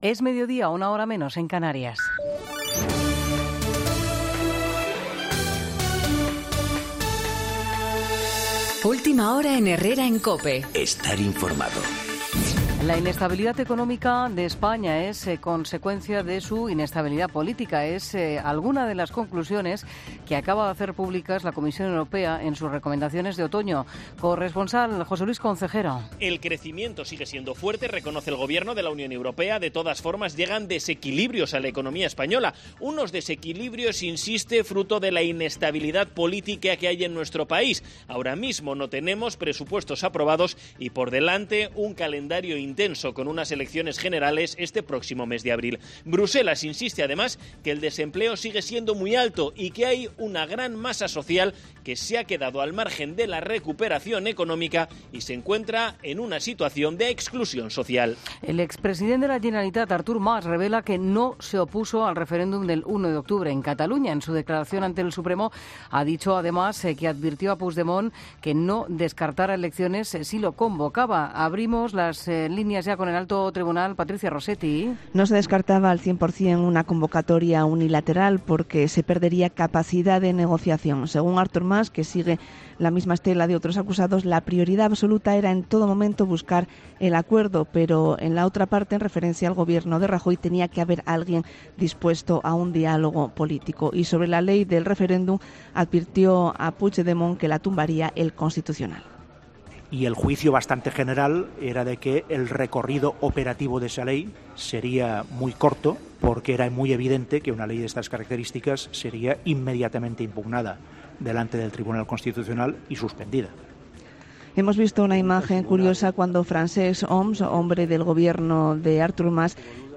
Boletín